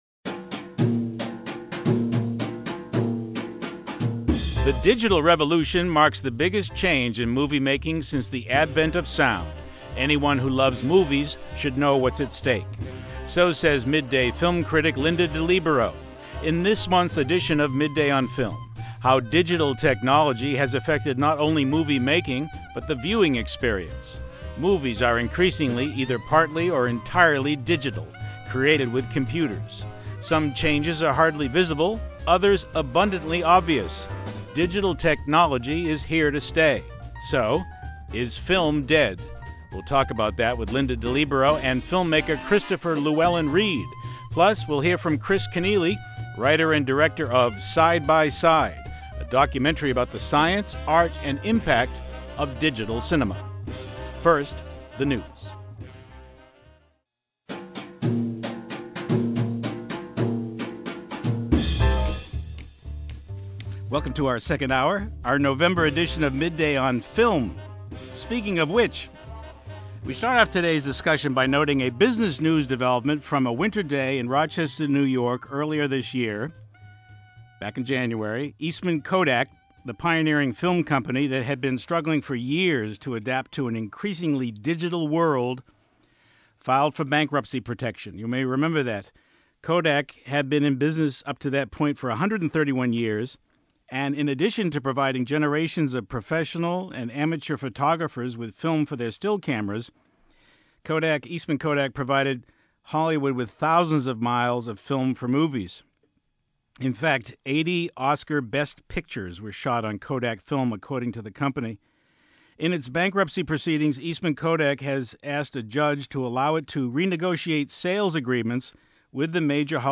who joined us for a bit, from Brooklyn, via phone). It was a great and lively discussion on a timely topic.